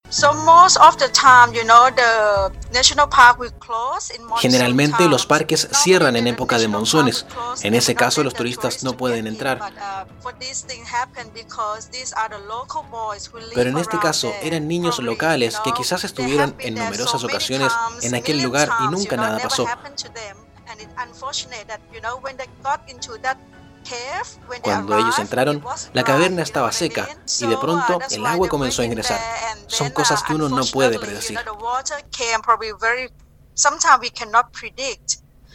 Radio Sago conversó con fotógrafa tailandesa tras exitoso rescate de niños en el país asiático - RadioSago